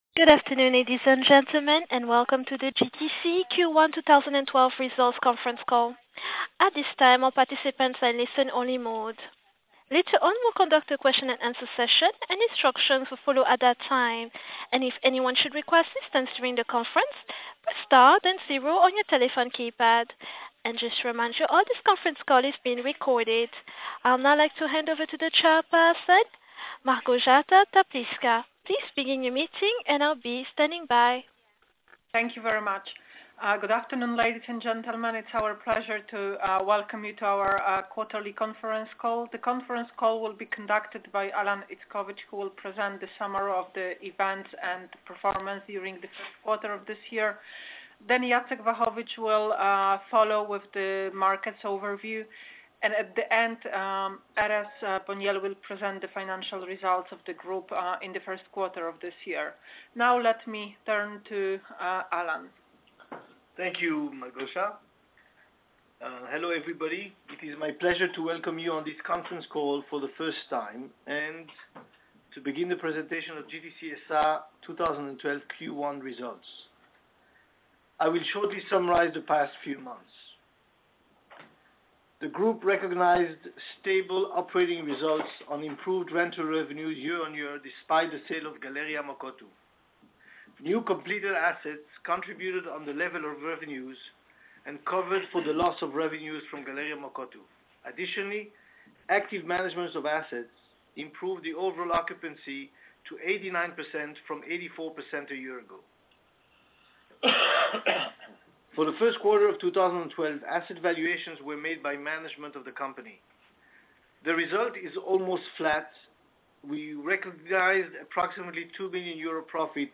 Wyniki za okres 3 miesiecy zakończony 31 marca 2012 r. (telekonferencja w języku angielskim)